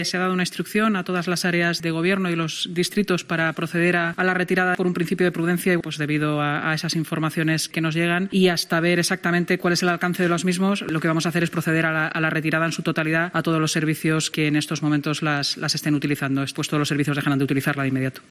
Delegada de Seguridad y Emergencias del Ayuntamiento de la capital, Inmaculada Sanz